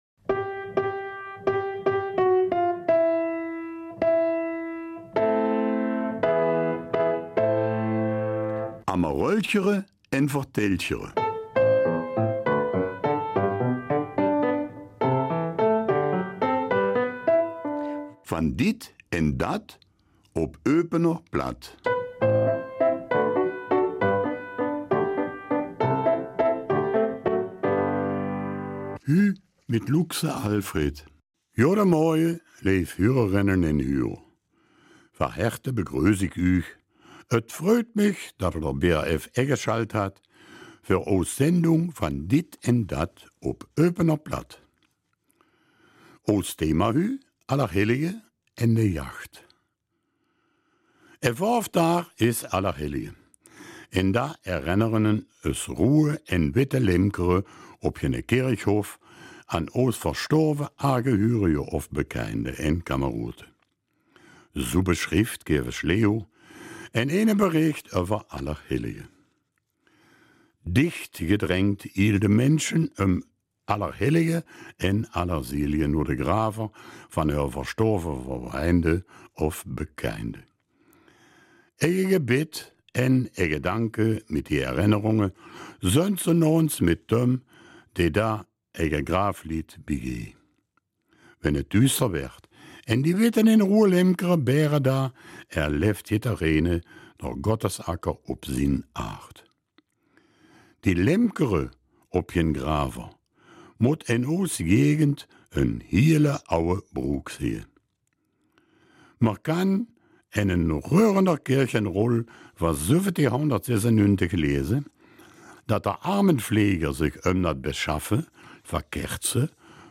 Eupener Mundart - 27. Oktober